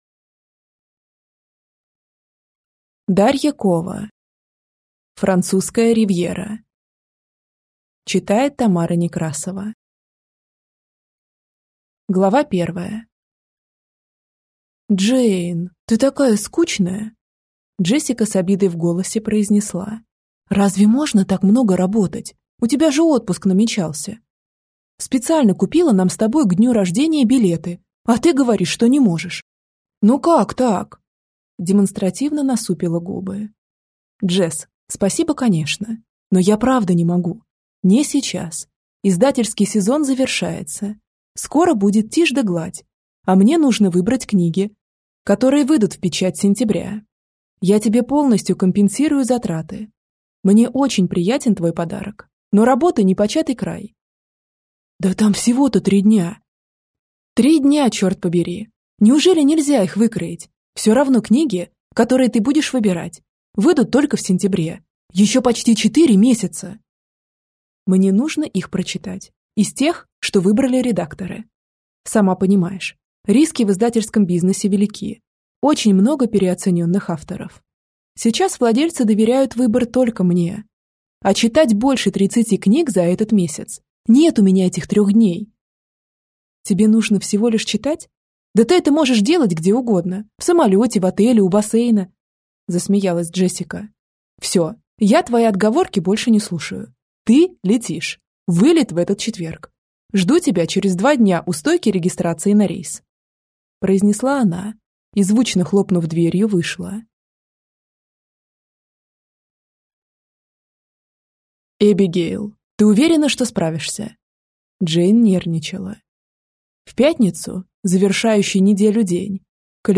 Аудиокнига Французская Ривьера | Библиотека аудиокниг